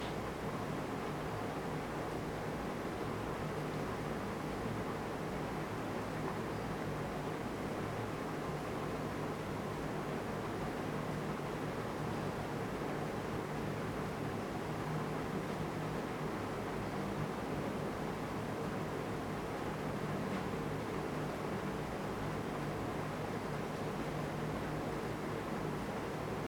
The following recordings are of Fan Coil Units in air conditioning units.
Noise levels WITH the Attenuator:
As you can hear, incorporating the Attenuator can offer up to a 13-decibel reduction in NR noise levels.
FCU-with-attenuator.mp3